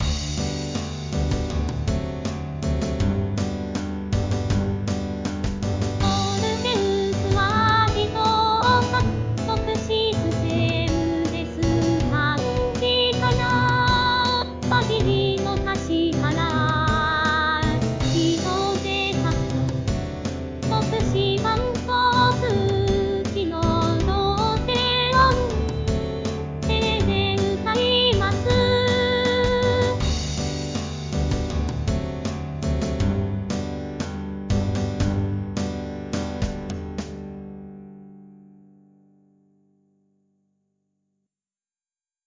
自動で作曲し、伴奏つきの
合成音声で歌います。